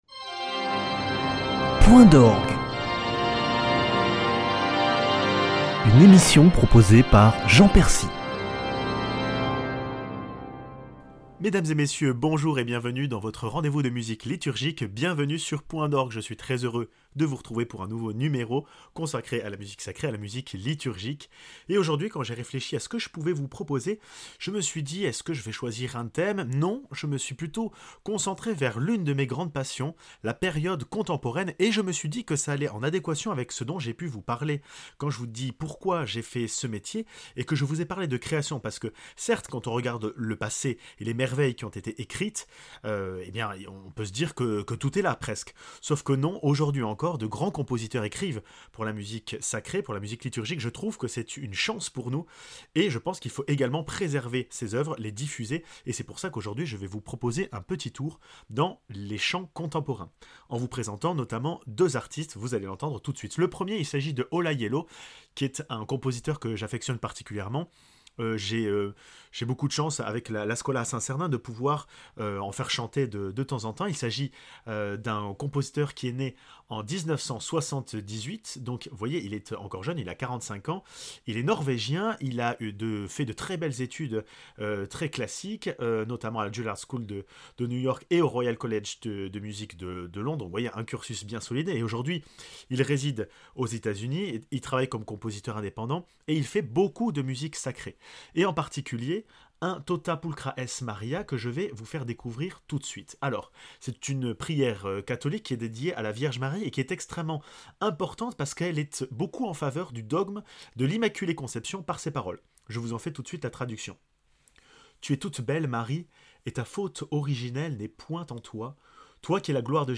Chant contemporain